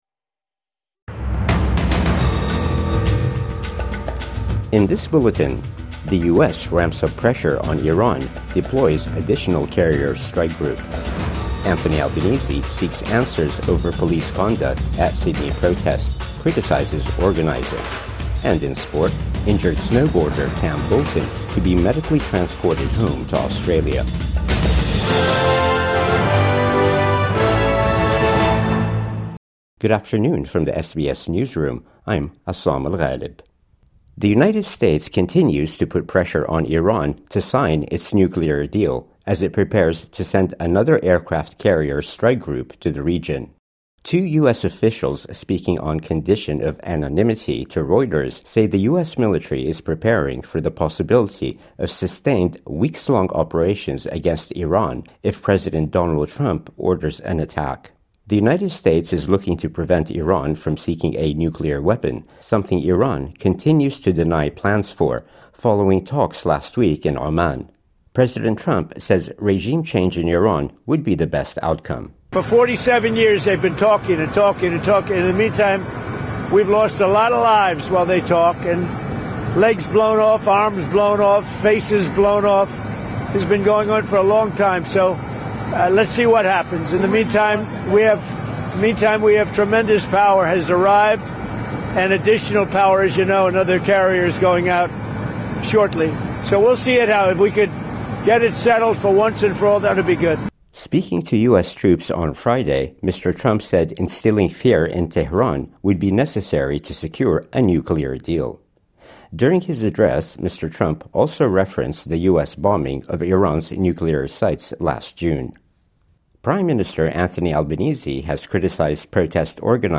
US ramps up pressure on Iran, deploys additional carrier strike group | Midday News Bulletin 14 February 2026